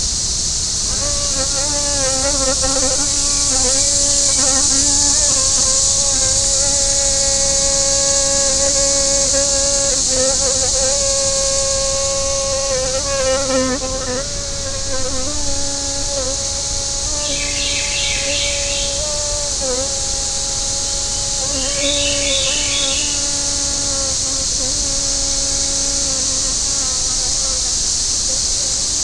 The moniker was given to the insect for its habit of zipping through the air toward a human then holding steady, imparting the news in a loud buzz.
The fly was perched on a leaf, washing its forelegs. Suddenly it launched itself and flew to me to give The News. Once done, the fly darted away to gossip at a fallen tree, then shot out of earshot.
The background sounds are cicadas and a Carolina wren.)
hoverfly.mp3